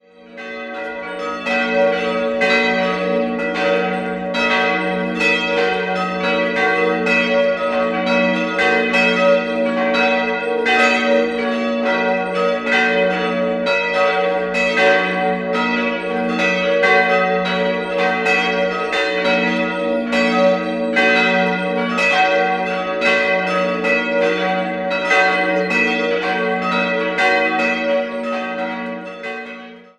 4-stimmiges Salve-Regina-Geläut: g'-h'-d''-e''